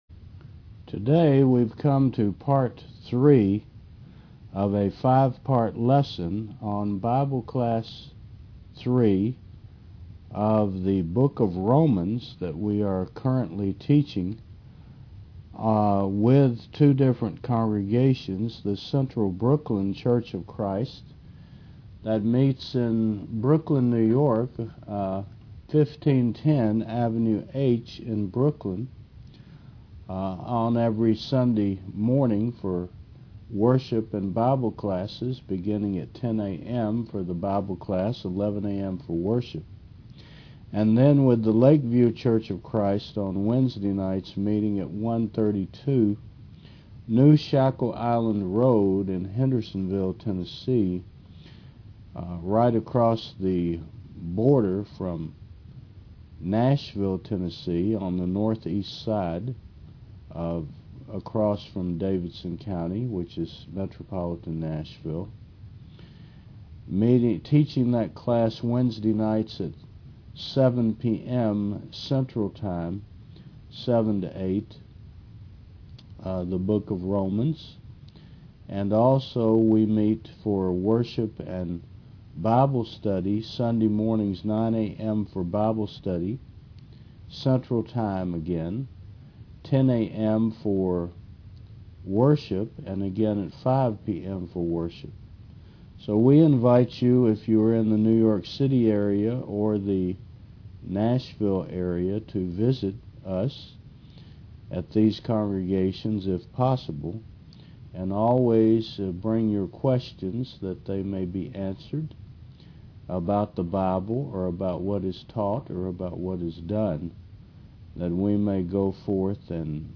Romans Classes